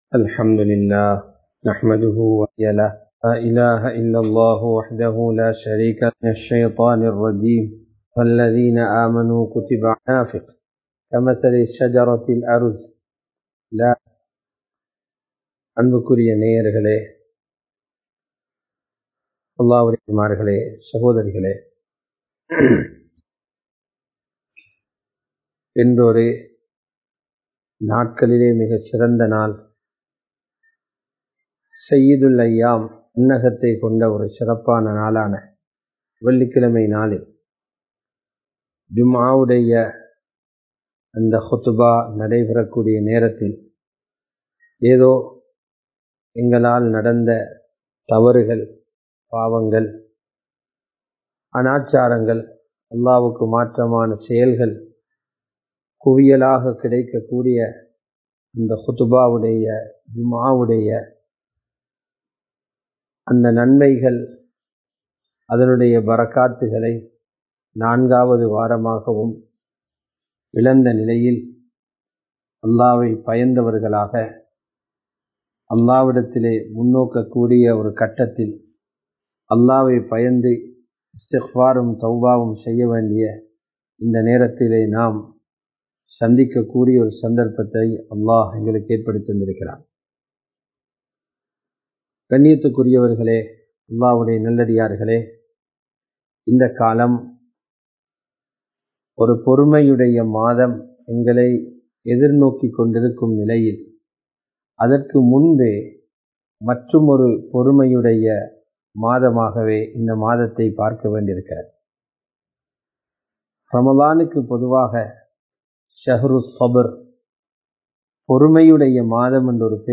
Nilaimaihal Maarakkoodiyathu (நிலைமைகள் மாறக்கூடியது) | Audio Bayans | All Ceylon Muslim Youth Community | Addalaichenai
Live Stream